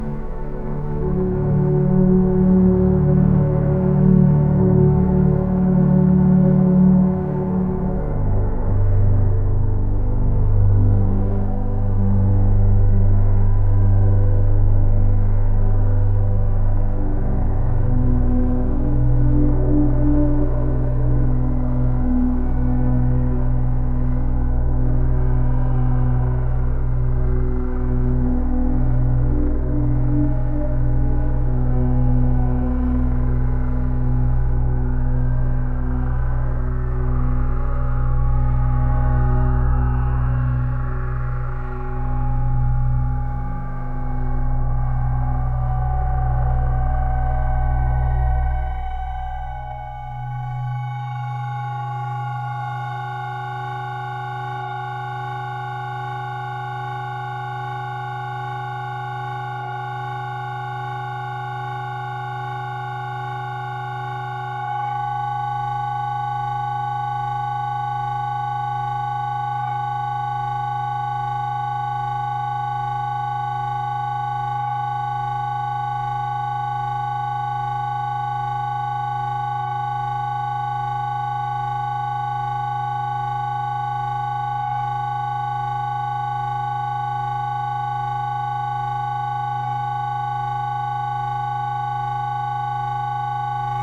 ethereal | electronic | ambient